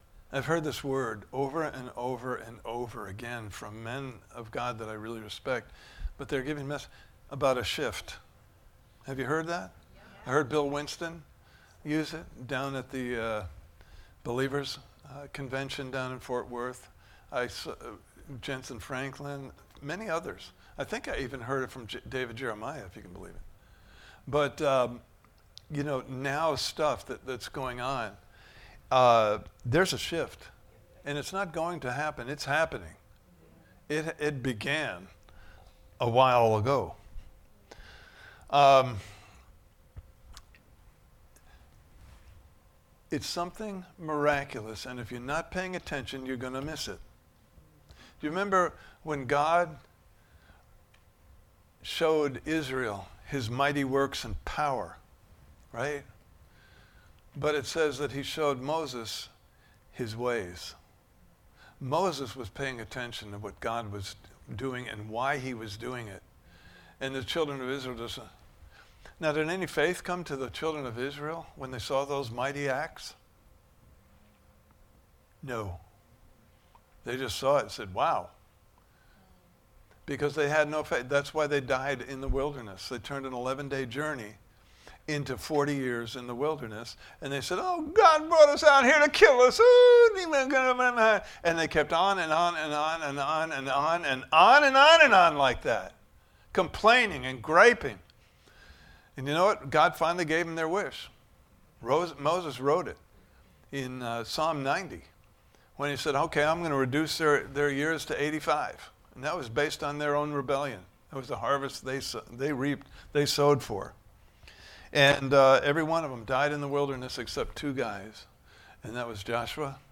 Prepared Service Type: Sunday Morning Service « Part 5